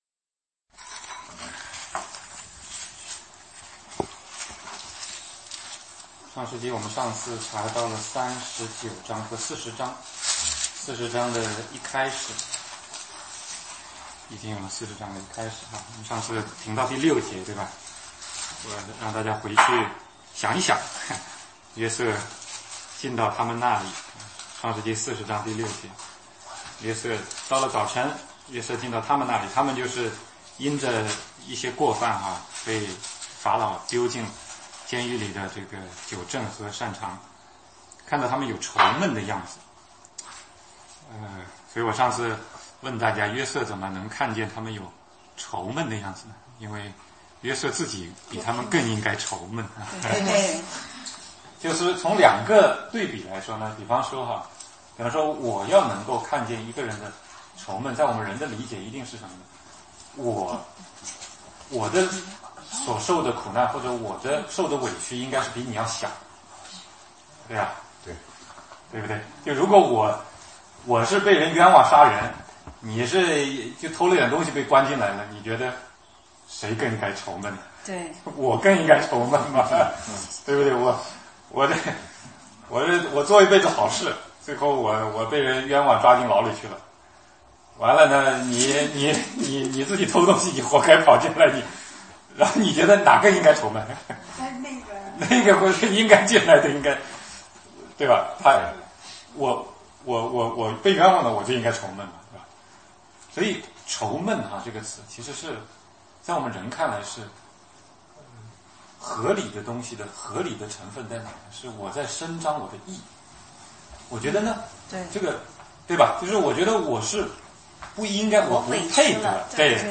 16街讲道录音 - 创世纪40 酒政与膳长